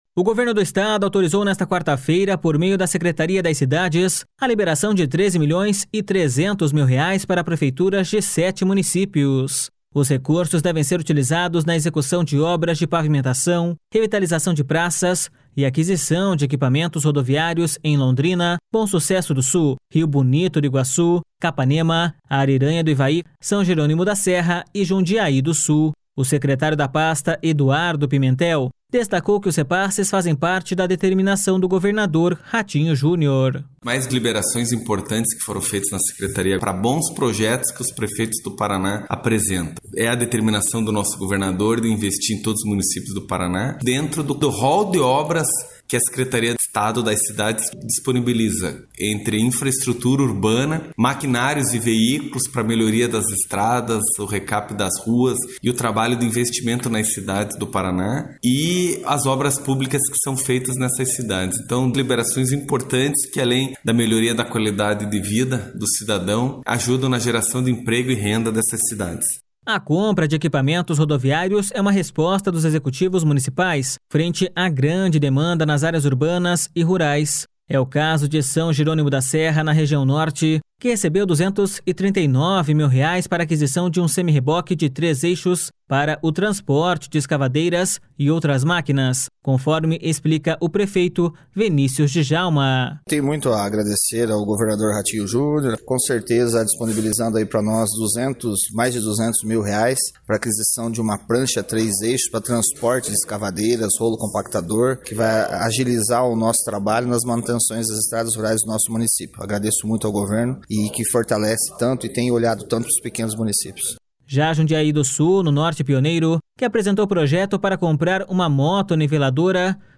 O secretário da pasta, Eduardo Pimentel, destacou que os repasses fazem parte da determinação do governador Ratinho Junior.// SONORA EDUARDO PIMENTEL.//
É o caso de São Jerônimo da Serra, na região Norte, que recebeu 239 mil reais para a aquisição de um semirreboque de três eixos para o transporte de escavadeiras e outras máquinas, conforme explica o prefeito Venícius Djalma.// SONORA VENÍCIUS DJALMA.//